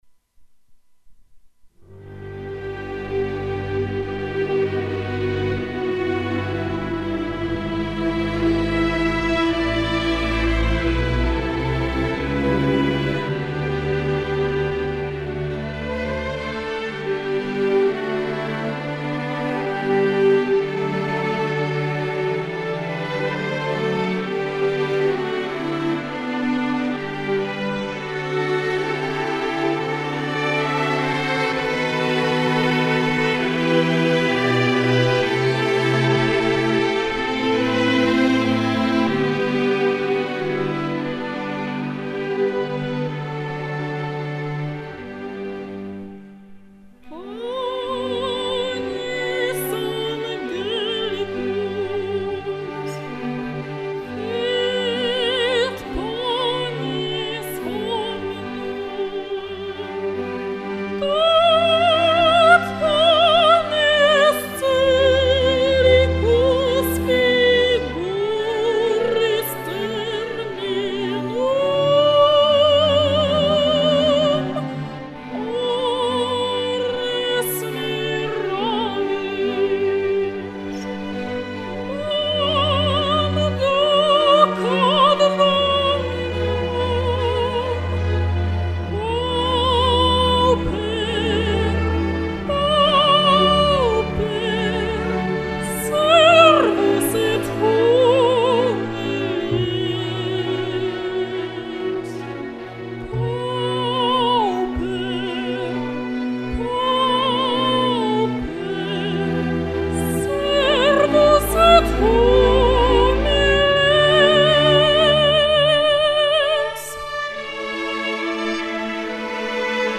Delikatny, kobiecy sopran, jakim zostałam obdarzona, upiększa swoim brzmieniem śluby i doniosłe uroczystości, jak również imprezy okolicznościowe i specjalne okazje.
PRZYKŁADOWE WYKONANIA - nagrania w studio